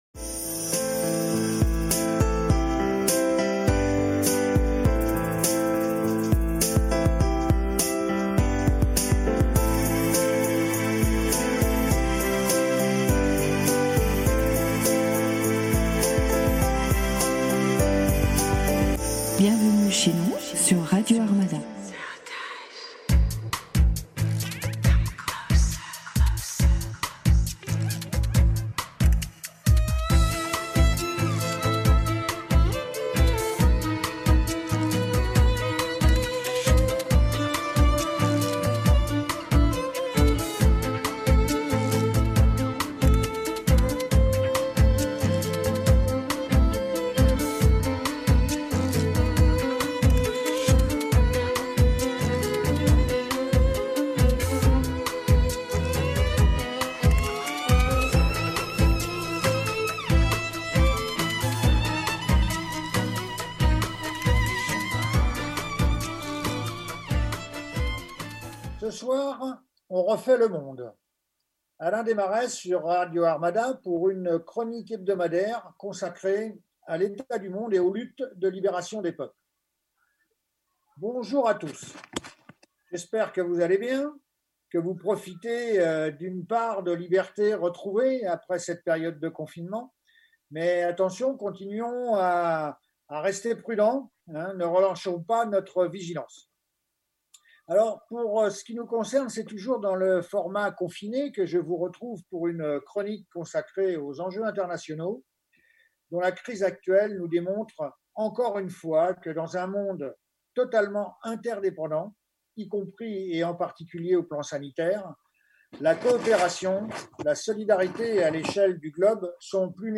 C’est toujours dans le format « confiné » que je vous retrouve pour une chronique consacrée aux enjeux internationaux dont la crise actuelle nous démontre encore une fois que dans un monde totalement interdépendant, y compris et en particulier au plan sanitaire, la coopération, la solidarité à l’échelle du globe sont plus nécessaires que jamais.
Afin d’évoquer le bilan de la situation en Europe, au sortir du confinement,j’ai invité le vice-président du parti de la Gauche Européenne (PGE)Sénateur, par ailleurs ancien directeur de l’Humanité et dirigeant national du PCF.